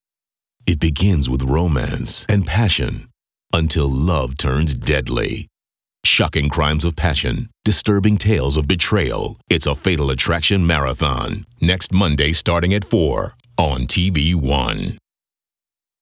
FA Marathon_Starting Next Monday15_VO.wav